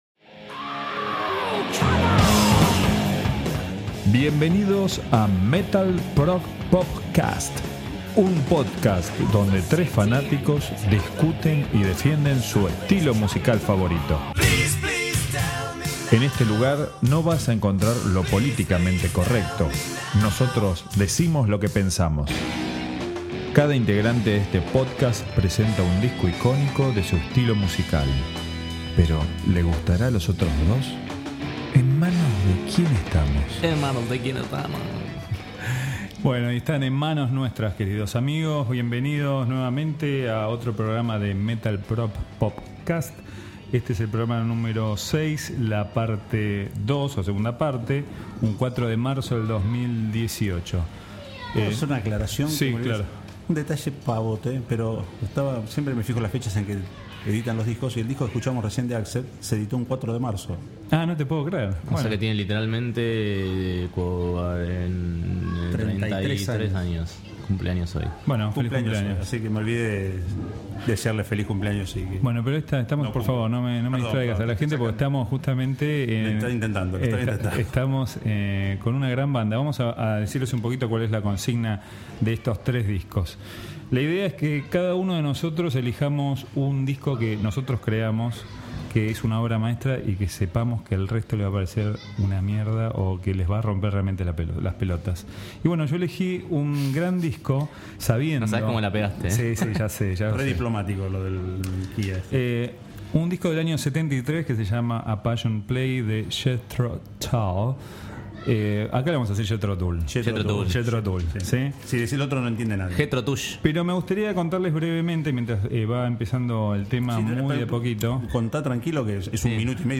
En MetalProgPop Cast nos juntamos 4 amigos para hablar y discutir sobre música.